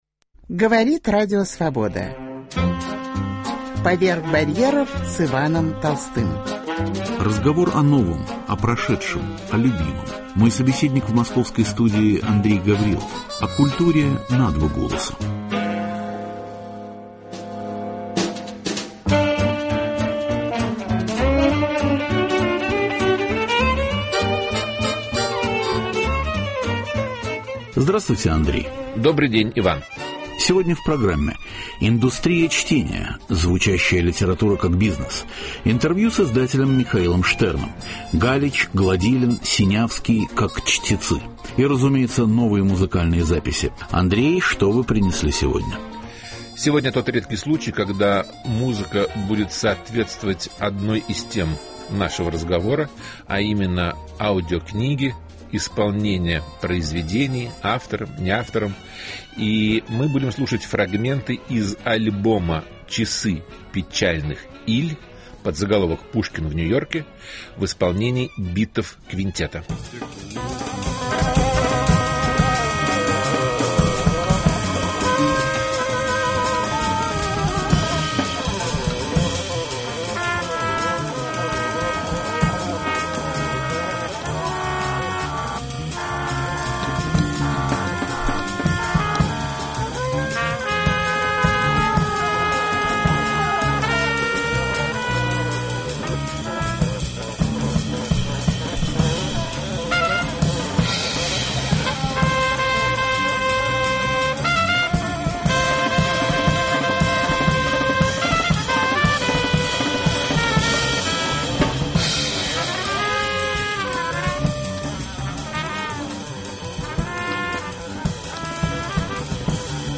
Индустрия чтения - звучащая литература как бизнес. Интервью
Галич, Гладилин, Синявский как чтецы. Новые музыкальные записи.